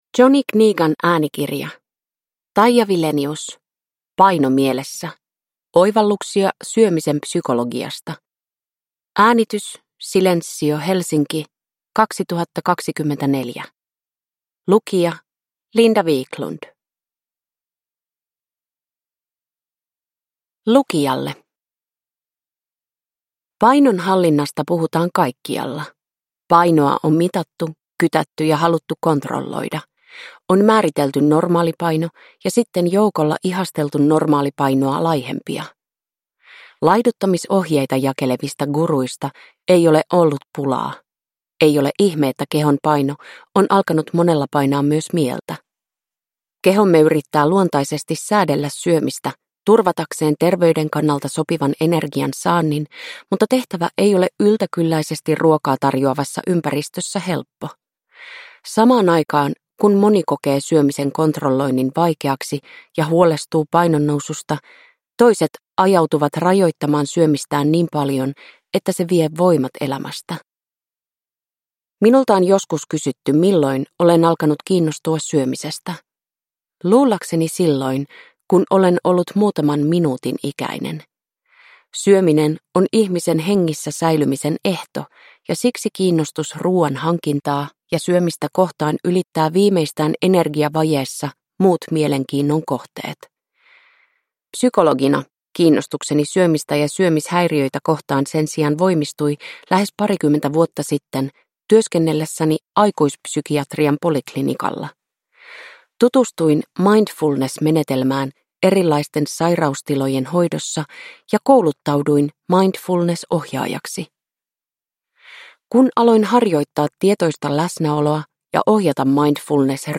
Paino mielessä – Ljudbok